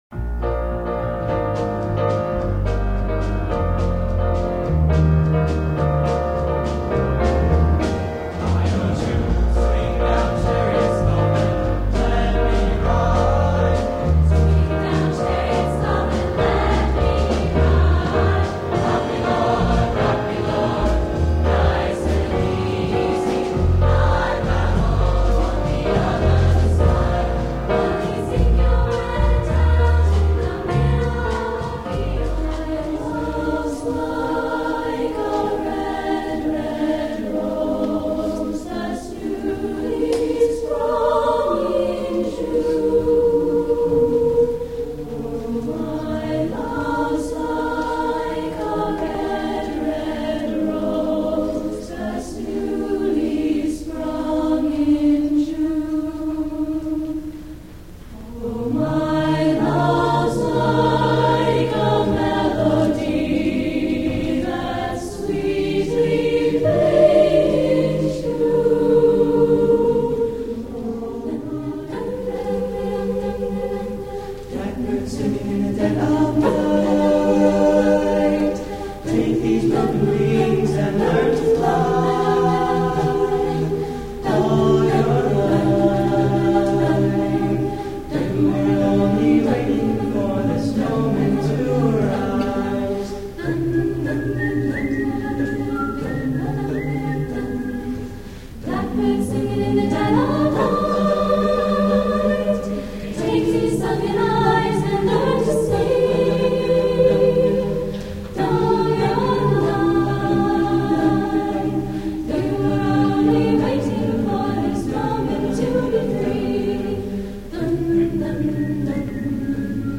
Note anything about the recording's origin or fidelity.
School Music (Chorus) 1997 Spring Chorus Concert Audio Sampler Spring Chorus Concert, 1997 (Wave, 3:19) *may take a few seconds to load* Spring Chorus Concert, 1997 (Mp3 LINK, 3:19) *right click on link, choose "save link as"*